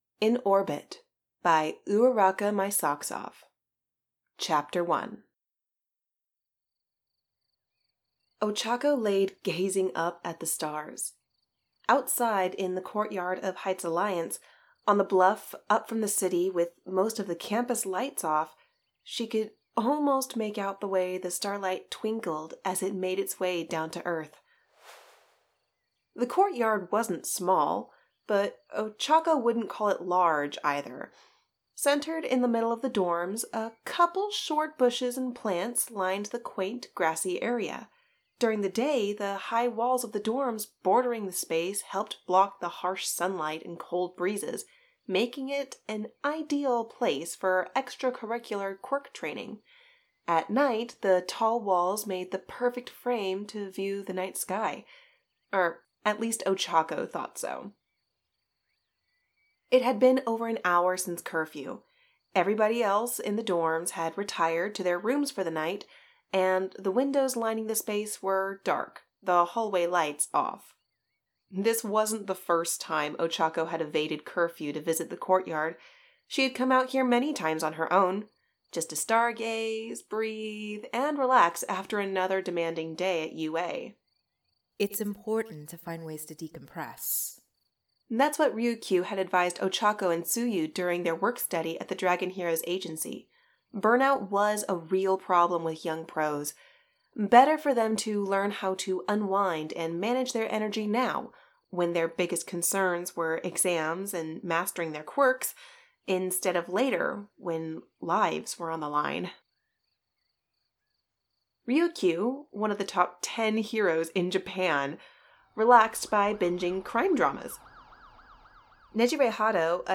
This chapter was voiced and produced by 5 of our contributors.